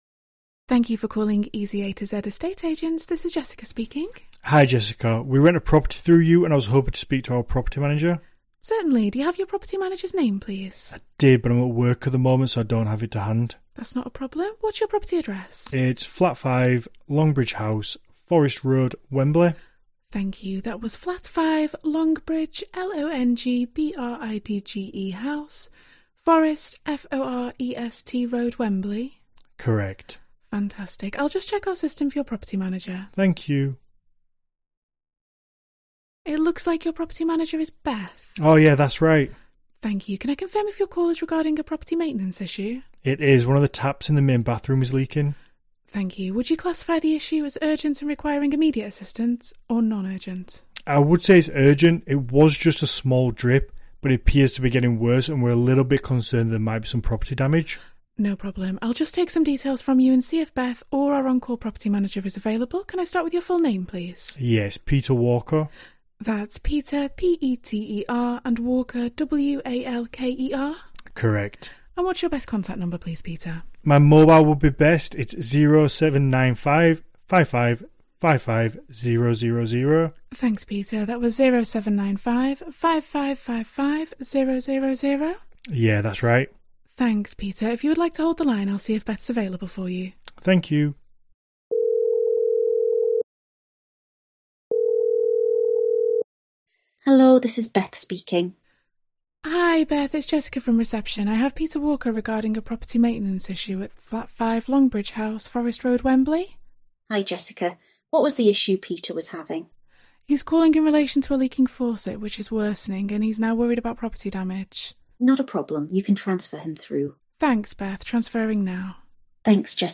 Calls are answered by professionals in the UK, with overflow support to ensure the fastest possible speed of answer.
phone-answering-virtual-assistant-sample-call-MyAssistant.mp3